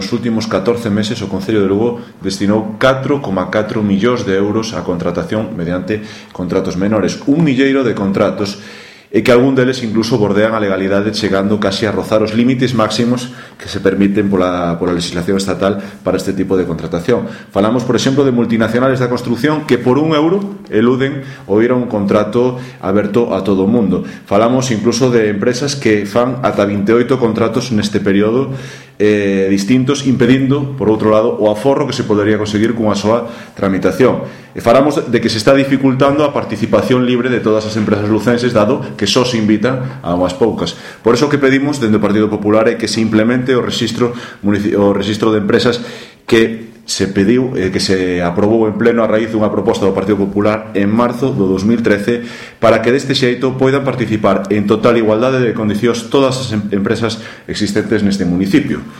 O vicevoceiro do Grupo Municipal do Partido Popular no Concello de Lugo, Antonio Ameijide, denunciou esta mañá en rolda de prensa o “abuso sistemático” do goberno local aos contratos menores, aos que destina unha media diaria de 10.346,94 euros.